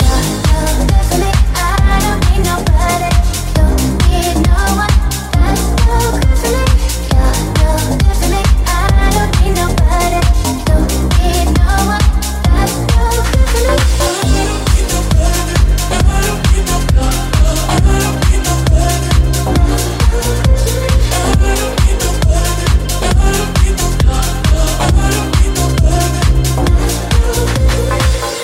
Genere: house,chill,deep,remix,hit